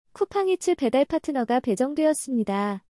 1. 배달파트너 배정 완료 목소리 알림
‘쿠팡이츠 배달파트너가 출발했어요’ 와 효과음이 같이 들려요.